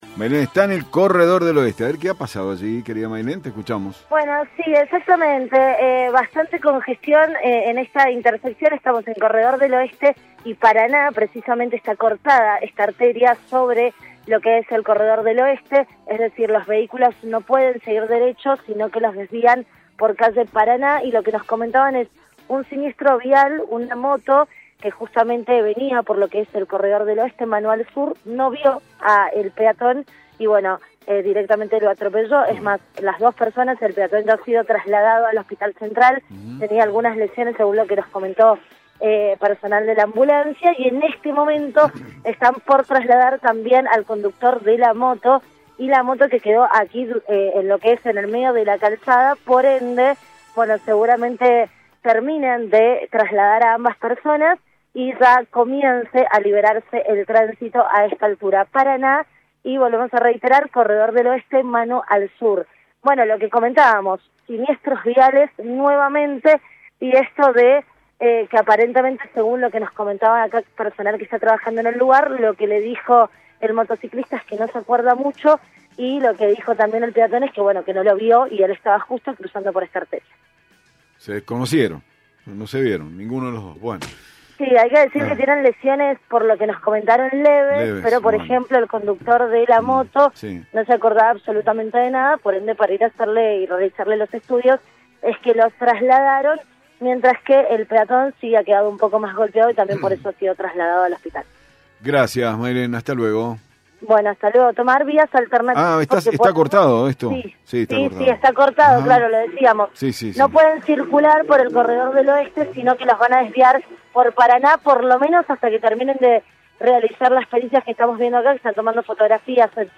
Móvil de LVDiez desde Corredor del Oeste